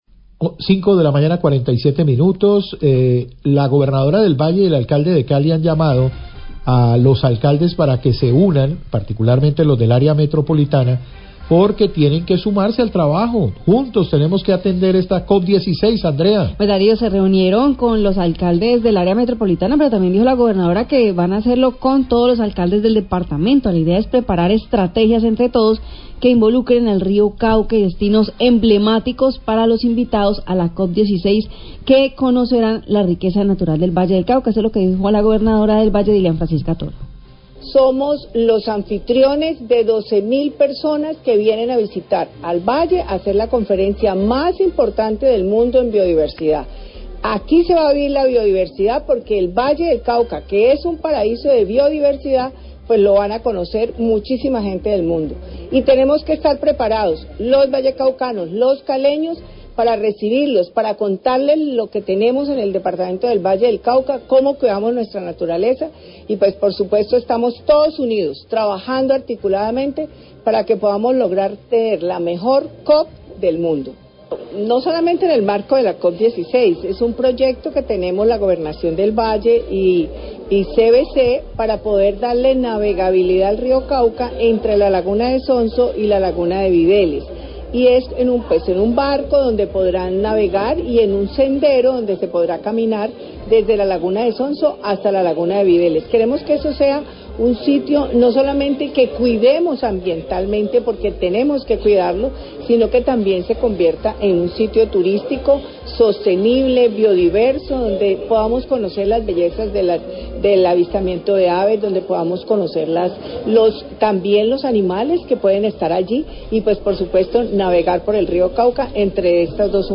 Radio
La Gobernadora dl Valle, Dilian Francisca Toro, habla inicialmente de la unión entre municipios del departamento para atender la presencia invitados a la COP 16. Luego se refiere al proyecto con la CVC para la navegabilidad en el Río Cauca, desde la laguna de Sonso hasta la Laguna Videles. El Alcalde de Cali, Alejandro eder, habla de los planes de turismo ambiental para los visitantes a la Cop16.